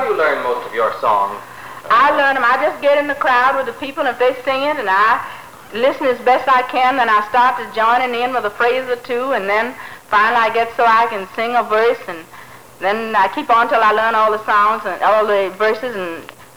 Zora in an interview